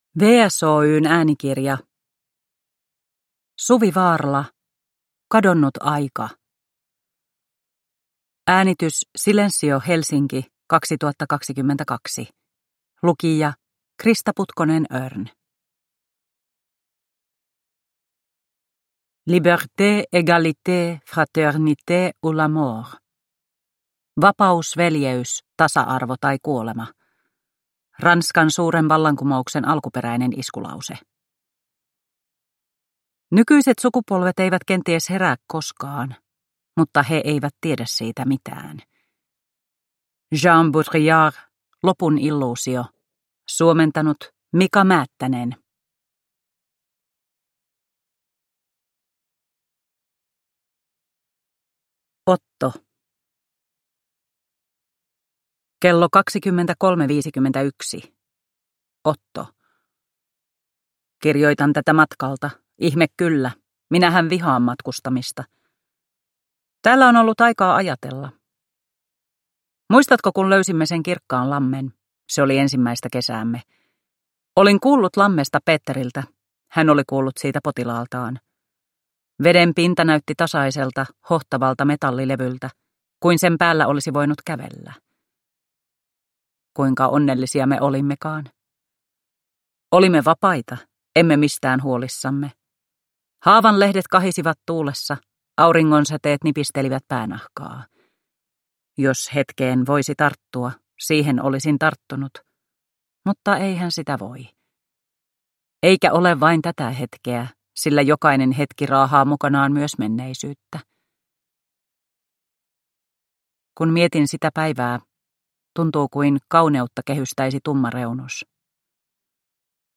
Kadonnut aika – Ljudbok – Laddas ner